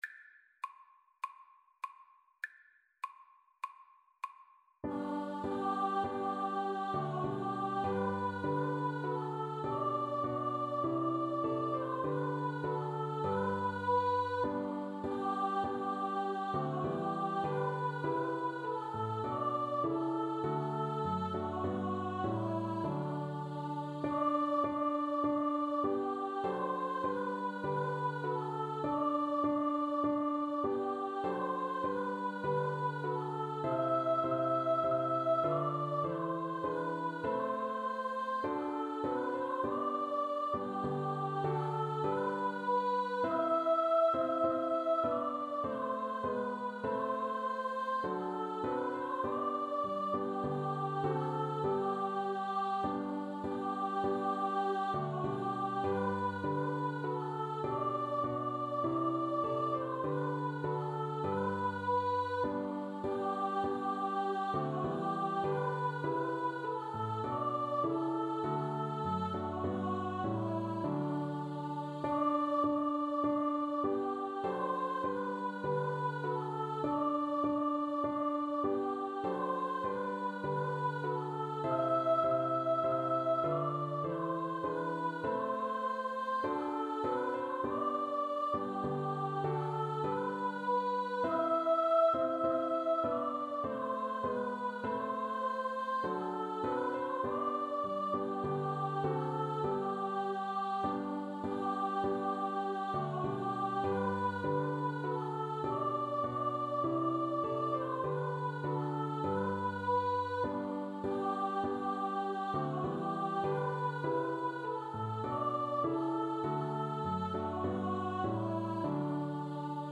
Free Sheet music for Choir (SATB)
G major (Sounding Pitch) (View more G major Music for Choir )
4/4 (View more 4/4 Music)
Traditional (View more Traditional Choir Music)
hark_the_herald_CHOIR_kar1.mp3